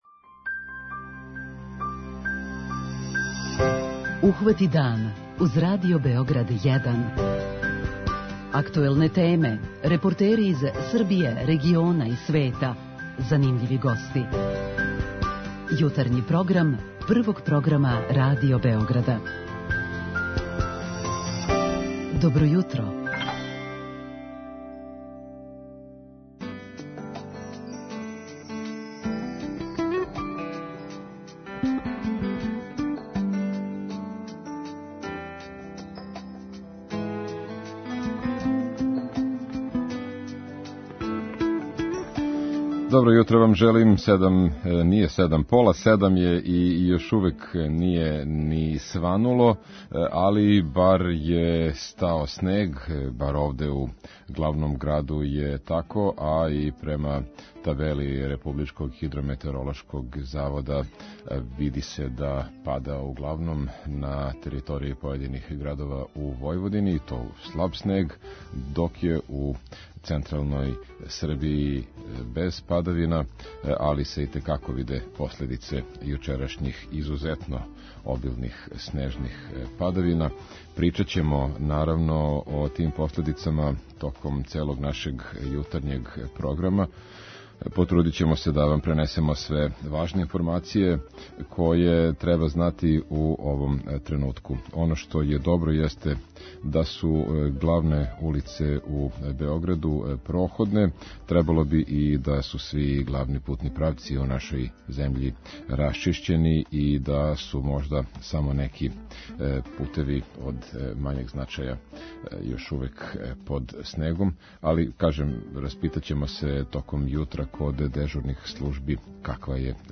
Пре неколико дана отворен је још један такав центар, а у плану је још. О томе ћемо разговарати с Наташом Станисављевић, градском секретарком за социјалну заштиту.
О томе ћемо разговарати с Наташом Станисављевић, градском секретарком за социјалну заштиту. преузми : 37.80 MB Ухвати дан Autor: Група аутора Јутарњи програм Радио Београда 1!